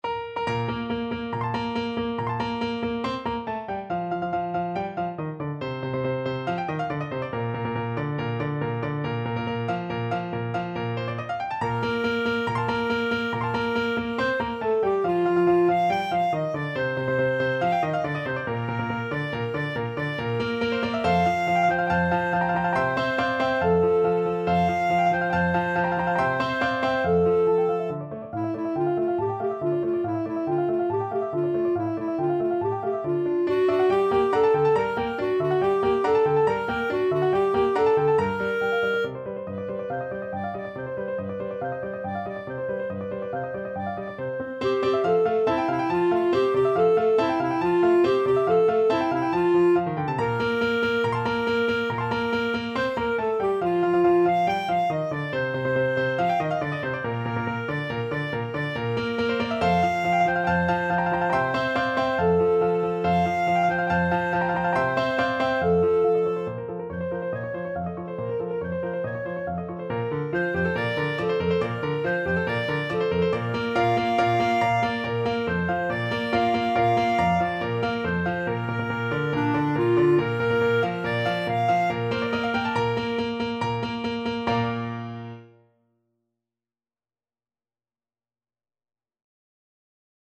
Clarinet
Bb major (Sounding Pitch) C major (Clarinet in Bb) (View more Bb major Music for Clarinet )
2/4 (View more 2/4 Music)
~ = 140 Allegro vivace (View more music marked Allegro)
D5-G6
Classical (View more Classical Clarinet Music)